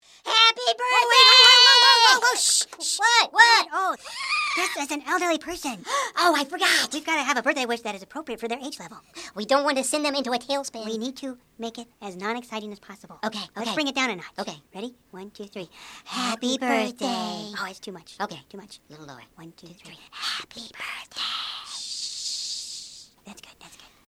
Card sound
• This is the only instance that Hoops and Yoyo whispers.